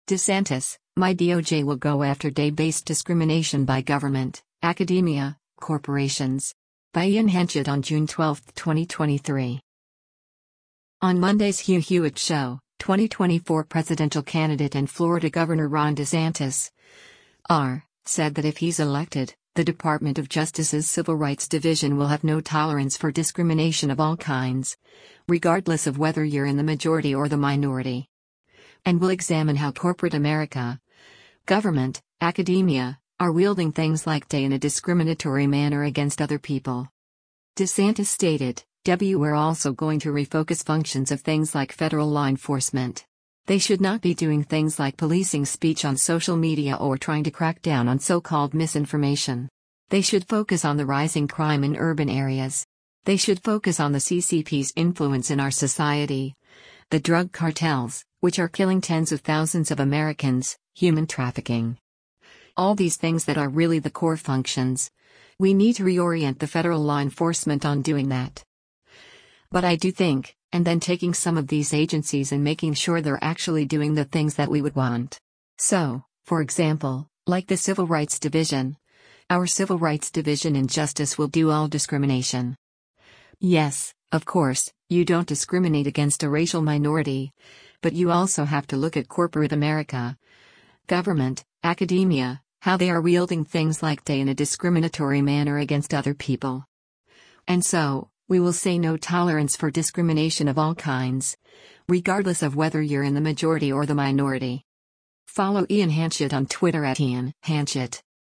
On Monday’s “Hugh Hewitt Show,” 2024 presidential candidate and Florida Gov. Ron DeSantis (R) said that if he’s elected, the Department of Justice’s Civil Rights Division will have “no tolerance for discrimination of all kinds, regardless of whether you’re in the majority or the minority.”